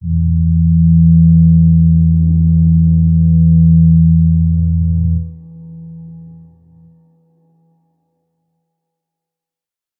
G_Crystal-E3-f.wav